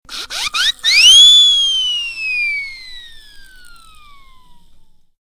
clock12.ogg